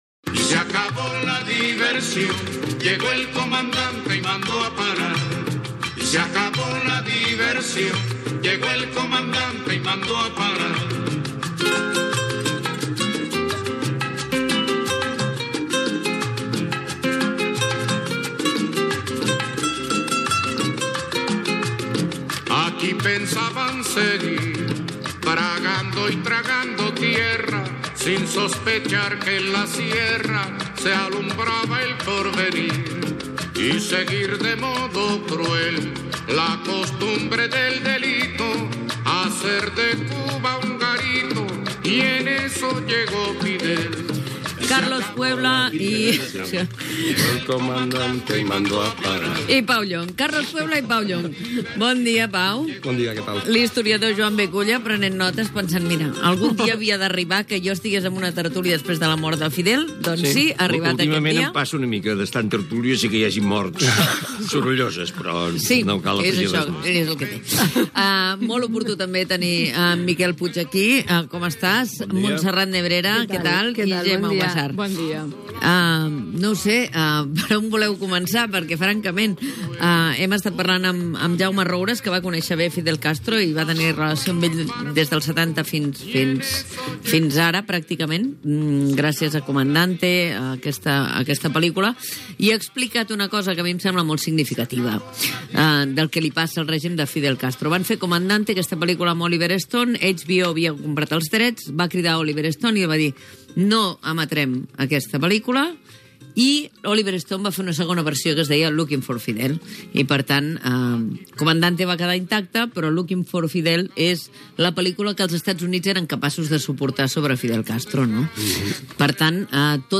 "La tertúlia"
Info-entreteniment